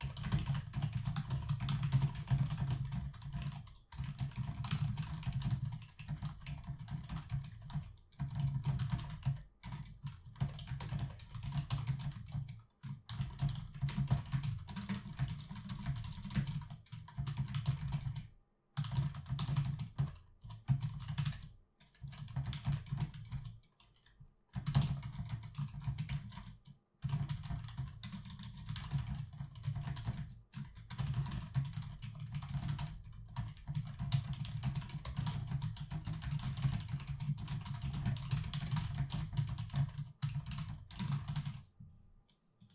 Keyboard Typing (Fast)
Fast Keyboard Typing sound effect free sound royalty free Memes